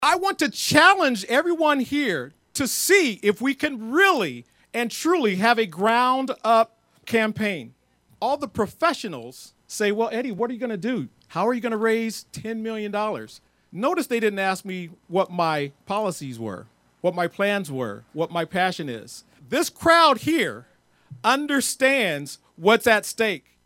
HE SPOKE TO DOZENS OF SUPPORTERS WEDNESDAY AT A KICK-OFF RALLY ON THE IOWA CAPITOL STEPS: